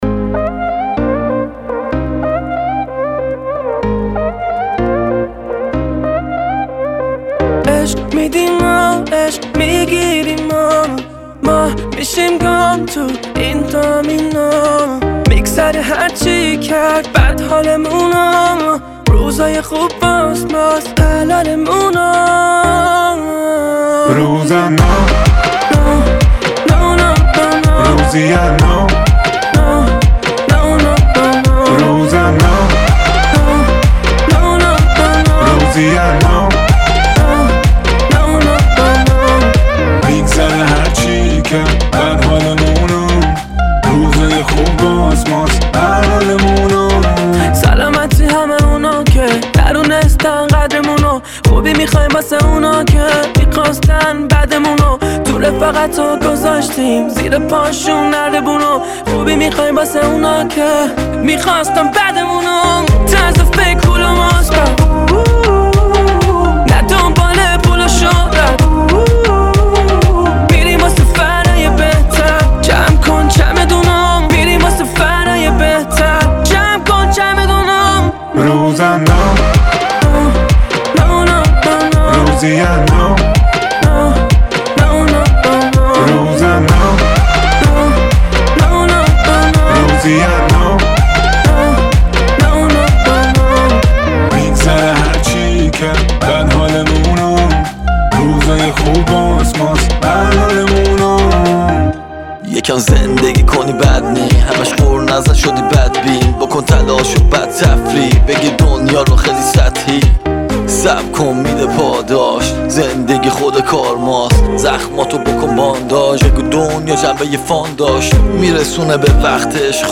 ژانر: رپ ، پاپ